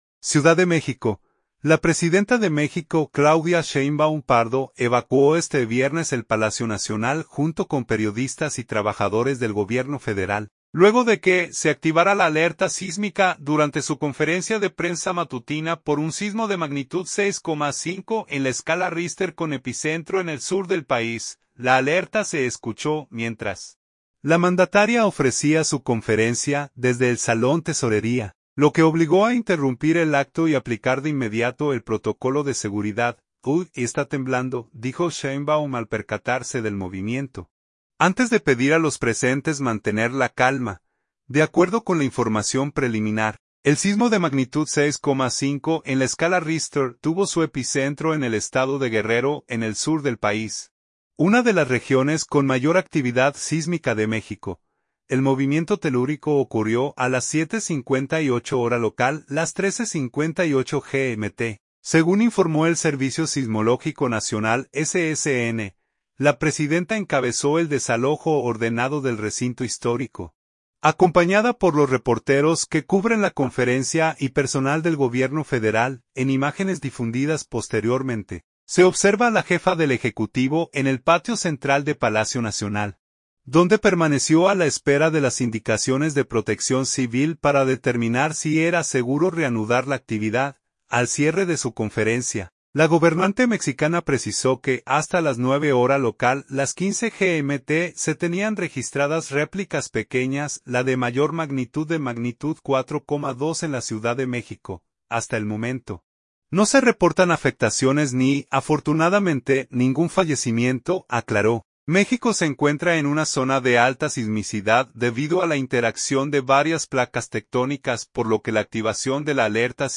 La alerta se escuchó mientras la mandataria ofrecía su conferencia desde el Salón Tesorería, lo que obligó a interrumpir el acto y aplicar de inmediato el protocolo de seguridad.
“Uy, está temblando», dijo Sheinbaum al percatarse del movimiento, antes de pedir a los presentes mantener la calma.